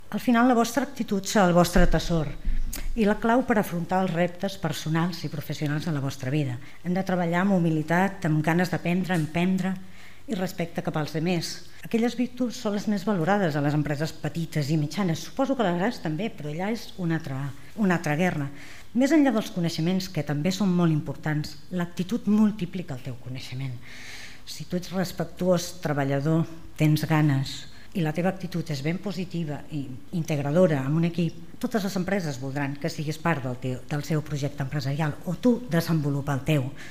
El lliurament dels premis, que es va fer a la Masia de Can Serraperera, forma part del Programa de Foment de la Cultura Emprenedora adreçat als instituts d’ensenyament secundari del municipi.